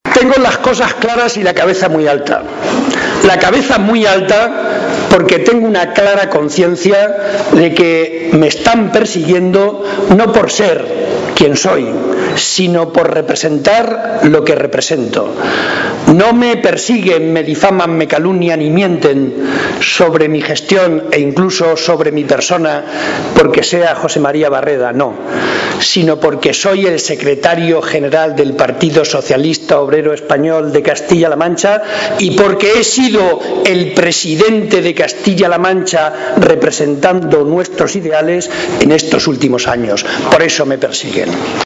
Barreda hacía estas declaraciones en el transcurso del Comité Provincial Extraordinario del PSOE de Ciudad Real donde ha sido ratificado como candidato número 1 al Congreso de los Diputados, un foro en el que ha denunciado la “campaña bestial” a la que está siendo sometido por parte de Cospedal y el resto de dirigentes del PP a base de “infamias, graves insultos y calumnias”.
Cortes de audio de la rueda de prensa